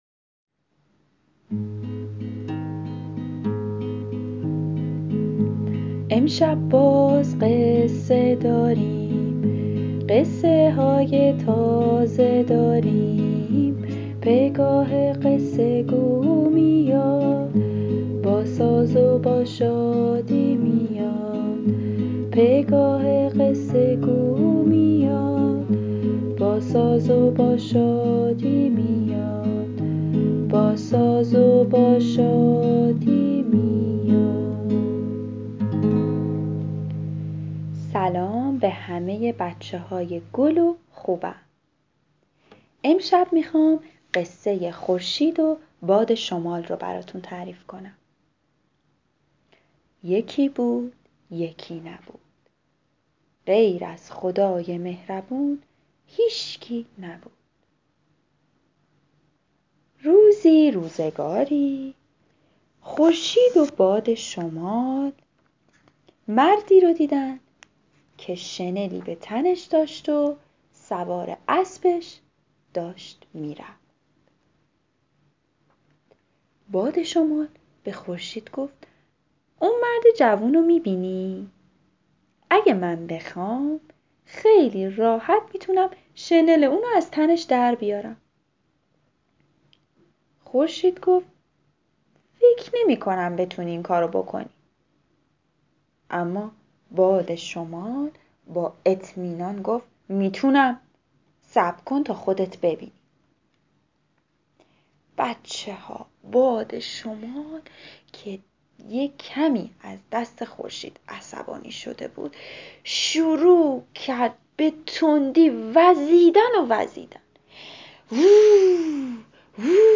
قصه صوتی کودکان دیدگاه شما 1,438 بازدید